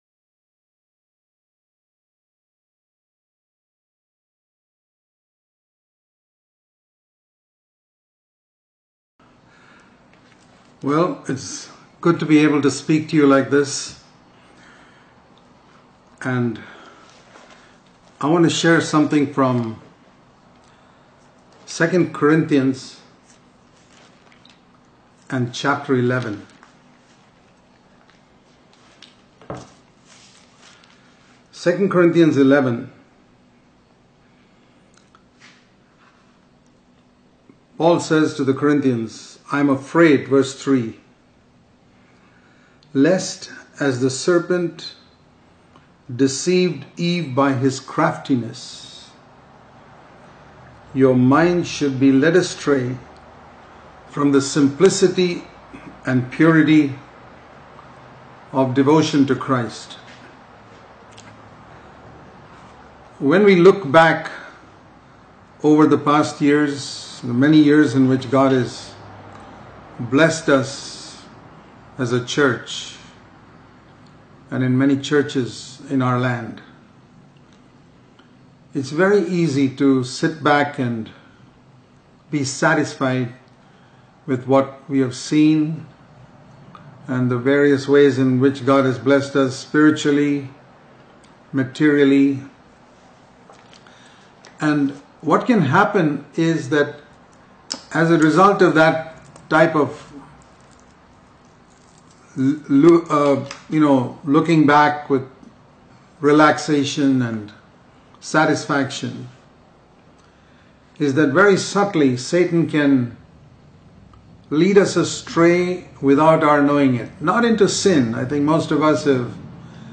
This sermon emphasizes the importance of maintaining a fervent devotion to Jesus Christ, highlighting the danger of being led astray by Satan from simple, pure devotion. It stresses the need to daily take up the cross, symbolizing the death of self-life, as the only way to access the Tree of Life, which represents devotion to Christ. The speaker warns against being deceived by emotional experiences or external actions without genuine love for Christ, emphasizing that true devotion is proven by dying to self in daily life.